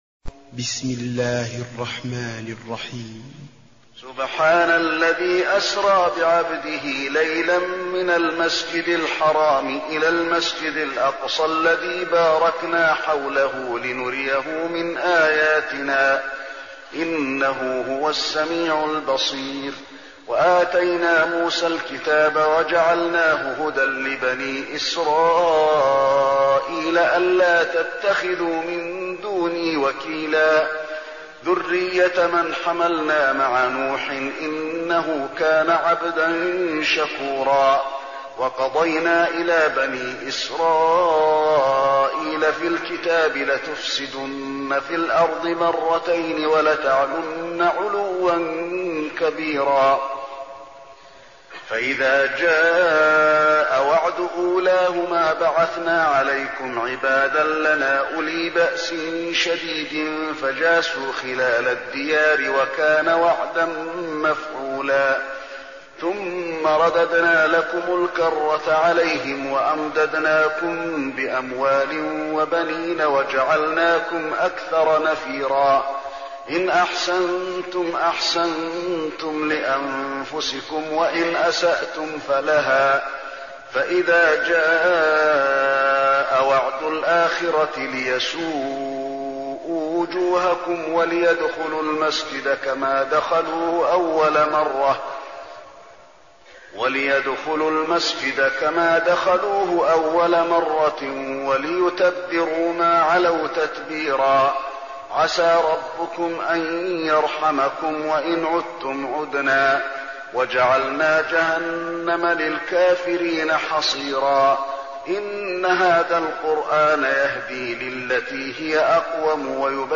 المكان: المسجد النبوي الإسراء